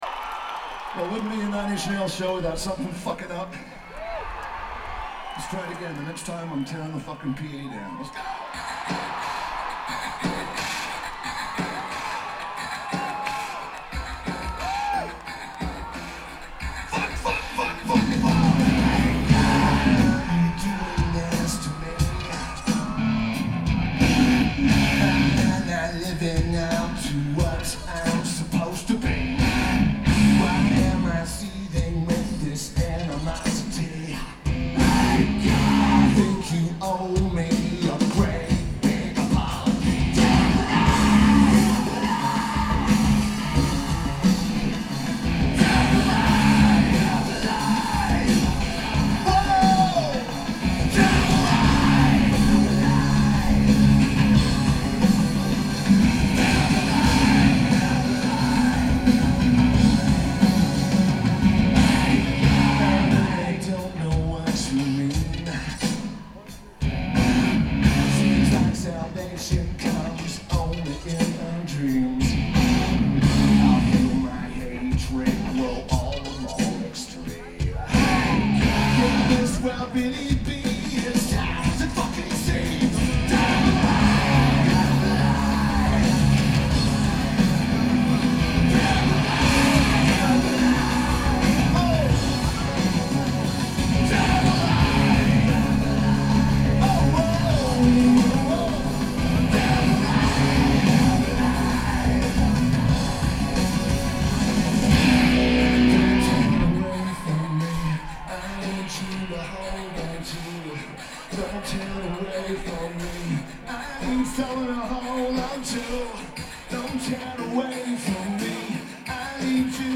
Cricket Wireless Amphitheater
Drums
Bass
Vocals/Guitar/Keyboards